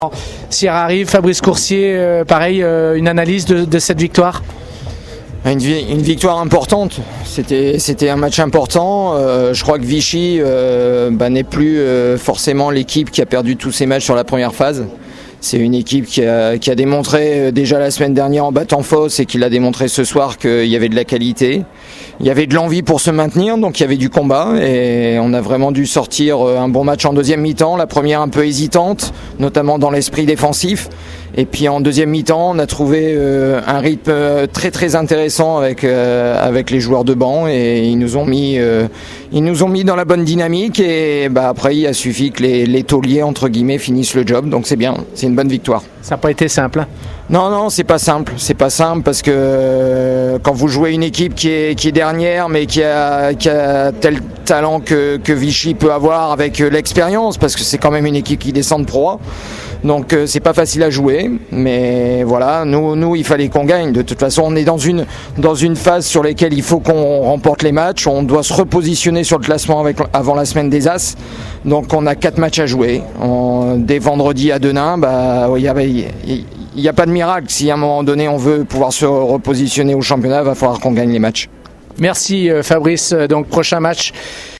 On écoute les réactions d’après-match